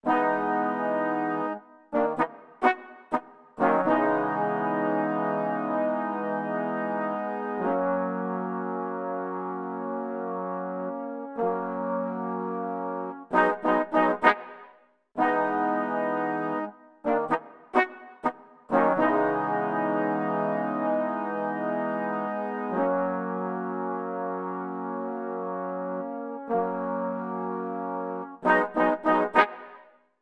14 brass 2 A2.wav